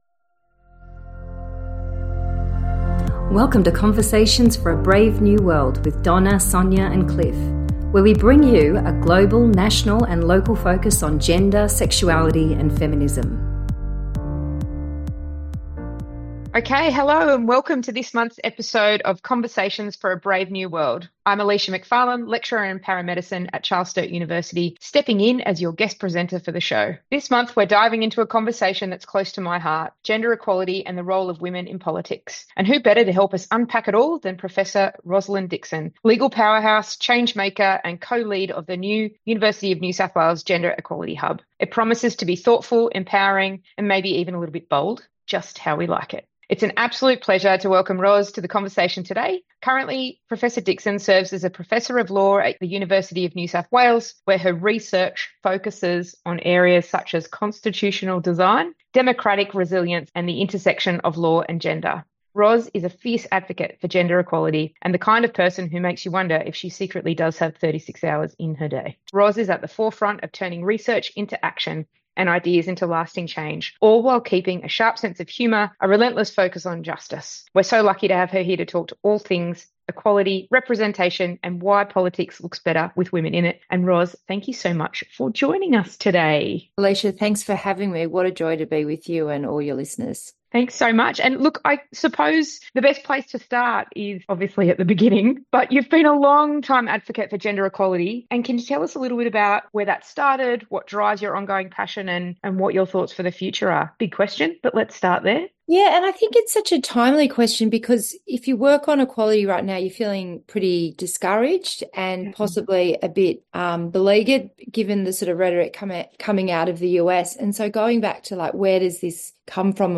Power, Progress, and Politics: A Conversation